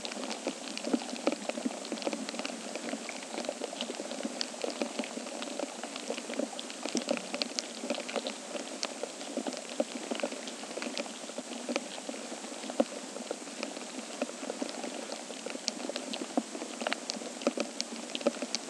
A.  Stored Product Insect movement and feeding sounds recorded for insect detection and monitoring studies
(the sound quality differences that you hear are caused by differences in the spectral ranges of the sensors).
link to piezorw1.wav Sitophilus zeamais adults in maize [731 kB, 10 s] recorded with microphone.